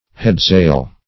Search Result for " headsail" : Wordnet 3.0 NOUN (1) 1. any sail set forward of the foremast of a vessel ; The Collaborative International Dictionary of English v.0.48: Headsail \Head"sail`\ (-s[=a]l`), n. (Naut.)